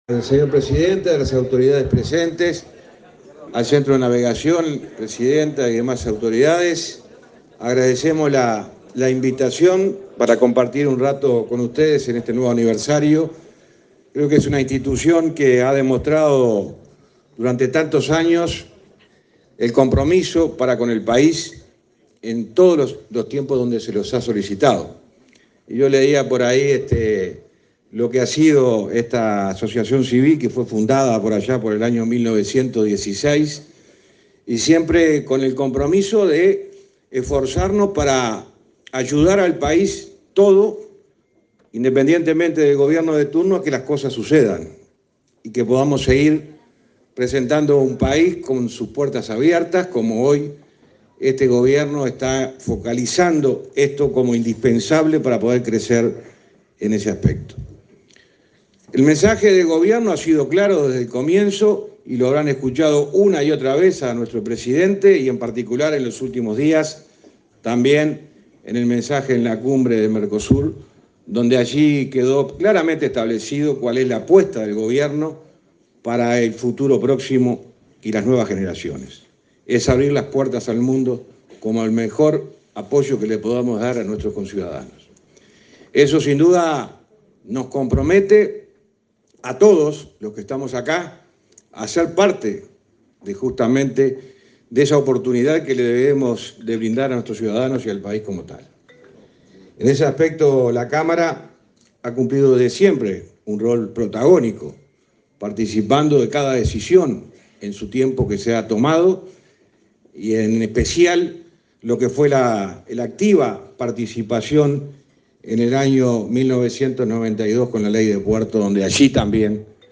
El ministro de Transporte, José Luis Falero, disertó este jueves 28 en el acto de celebración del 106.° aniversario del Centro de Navegación,